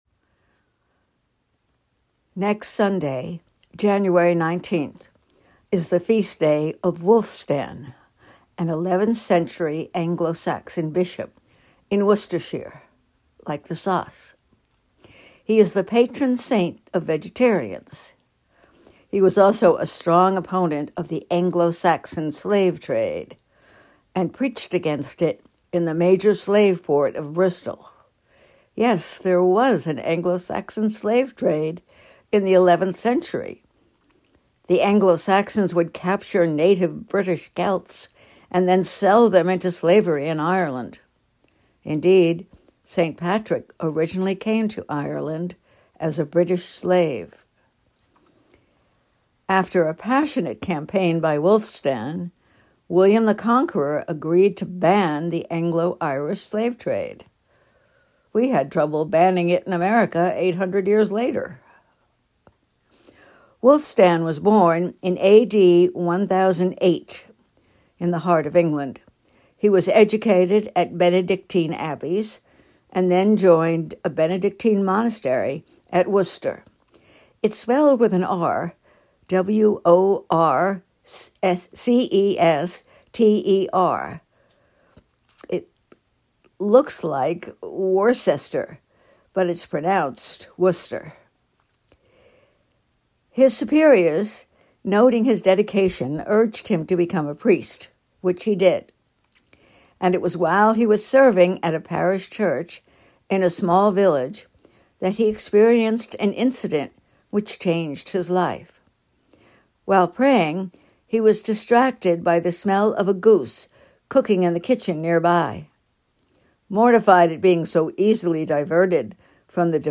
talk on Wulfstan.